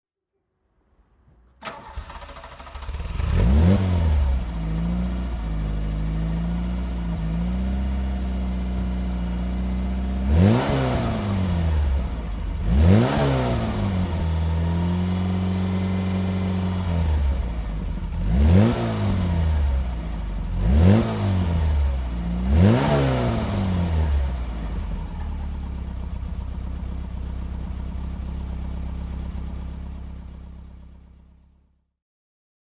Audi 50 GLS (1977) - Starten und Leerlauf